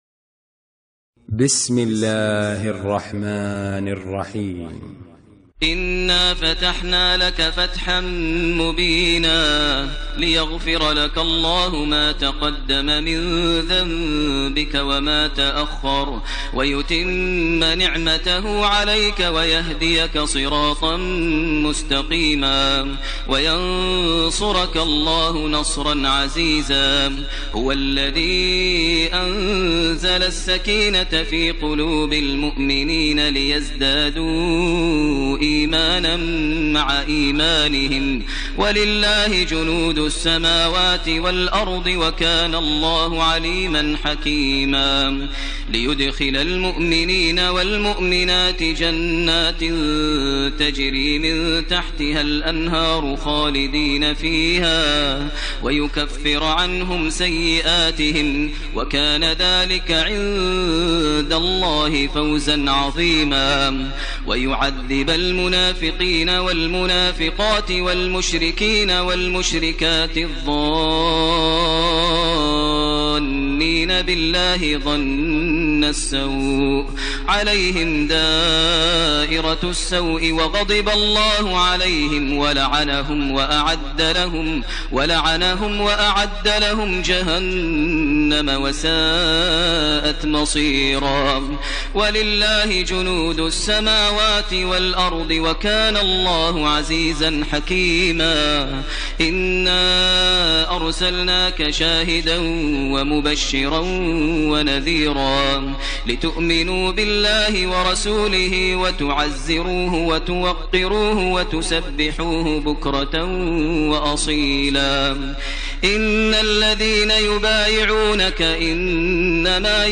ترتیل سوره فتح با صدای ماهر المعیقلی
048-Maher-Al-Muaiqly-Surah-Al-Fath.mp3